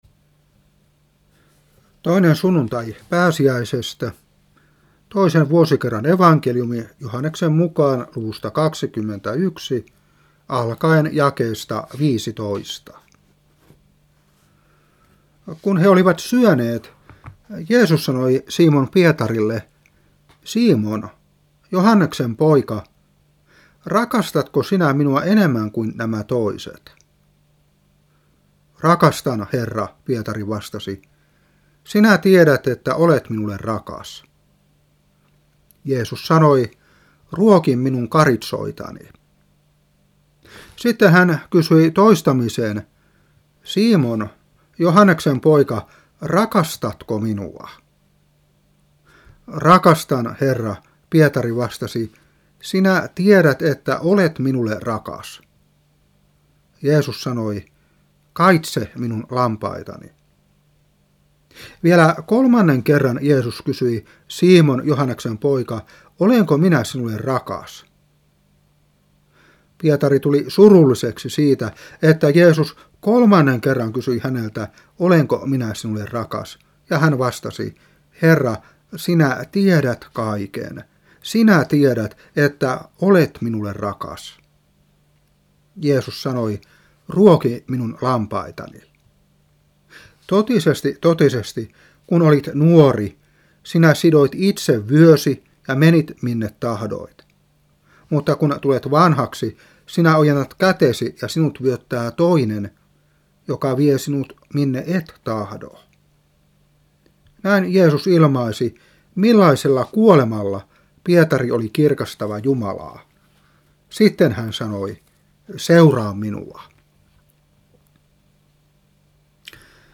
Saarna 1995-4. Joh.21:15-19.